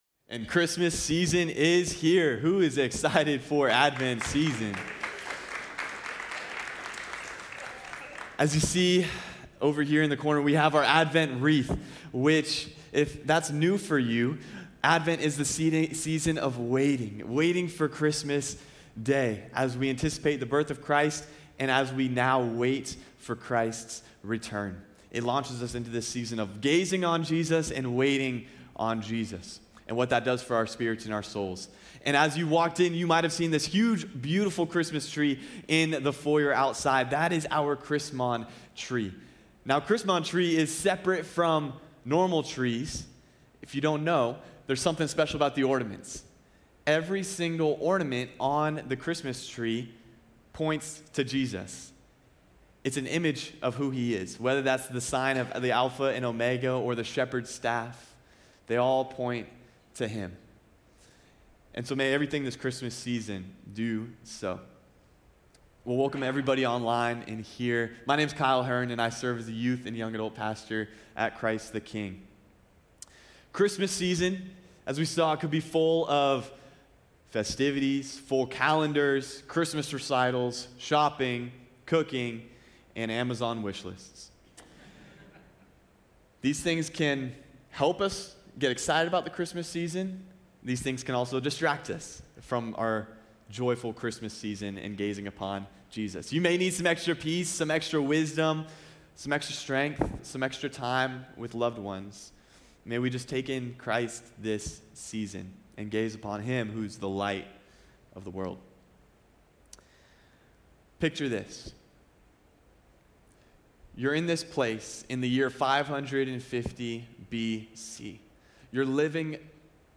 CtK-Sermon.mp3